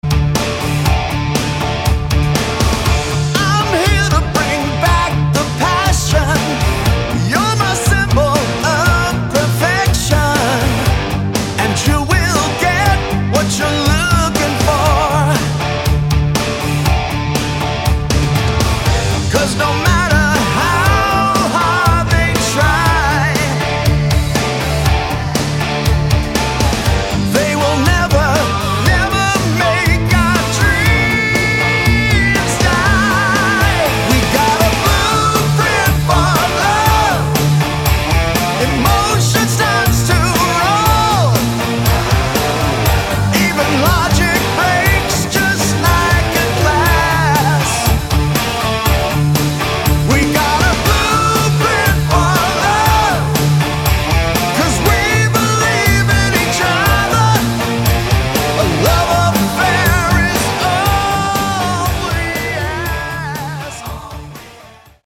Guitars & Keyboards
Lead & Backing Vocals
Bass & Drums
Melodic Rock